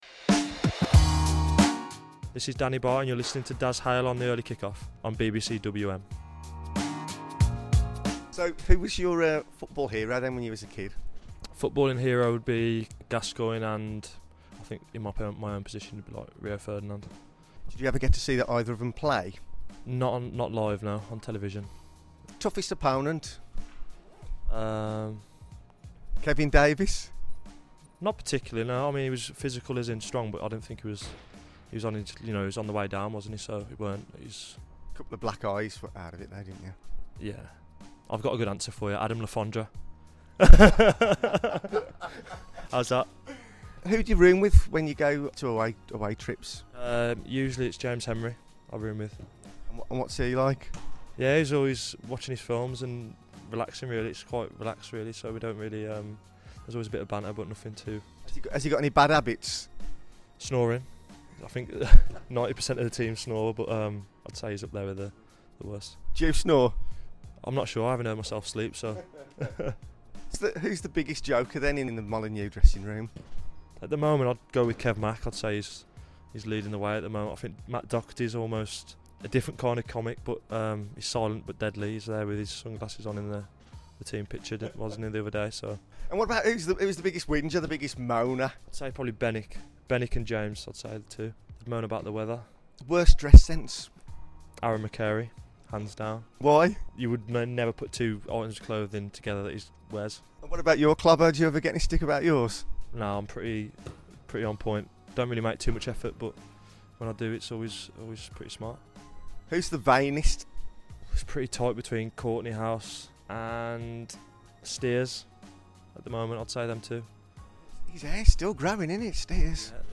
talks to defender Danny Batth.